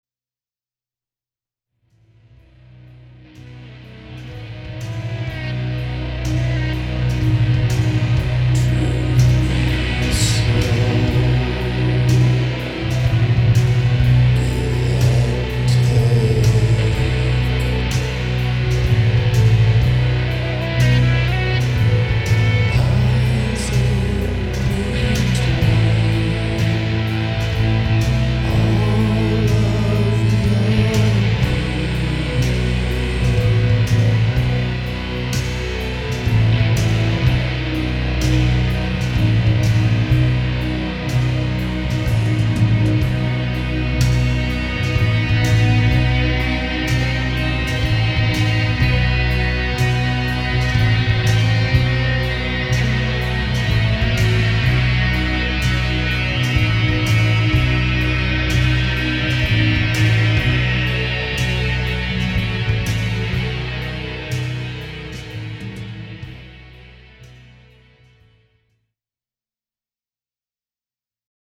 Recorded at Blue Moon Studio, Larry-O-Shack + The Drigh Room